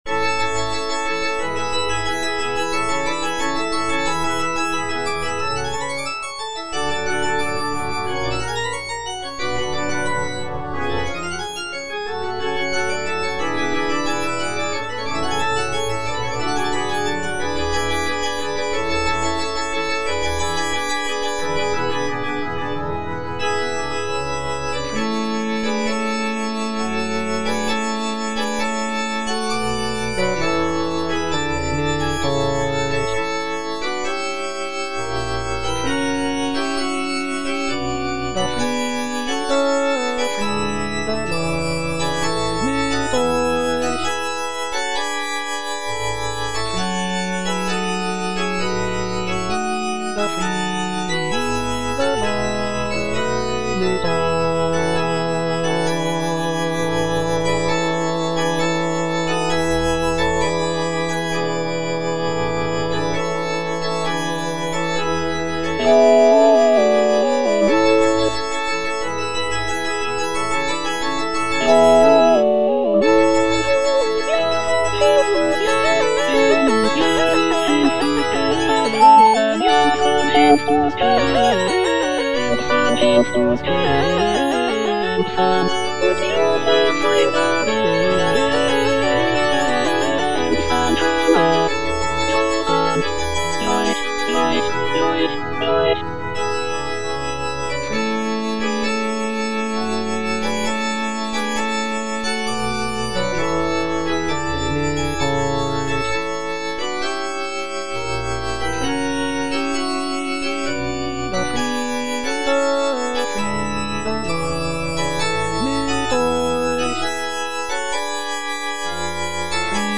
Cantata